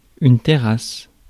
Ääntäminen
France: IPA: [tɛ.ʁas]